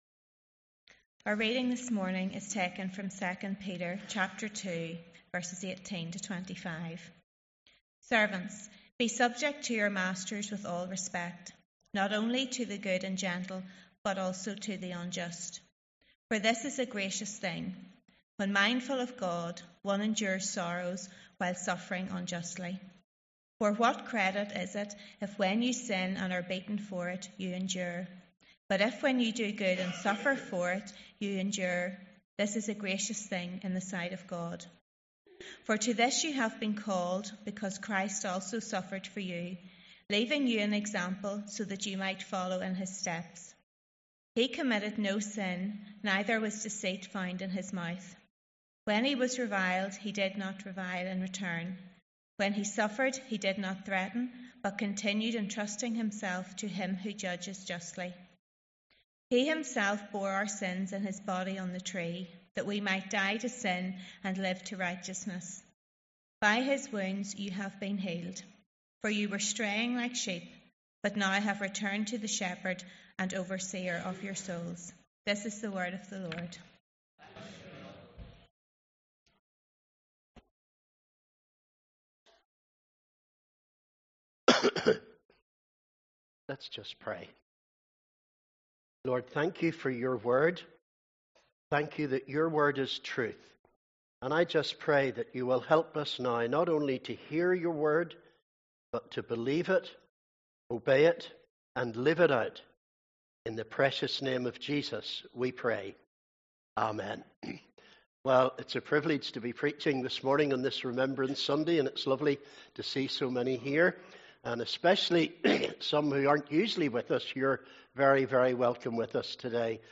Sunday 9th November – Remembrance Sunday